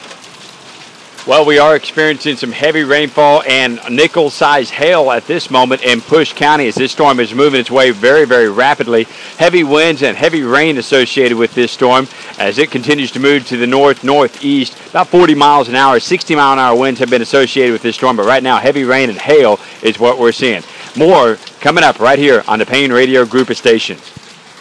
Severe weather report Push Co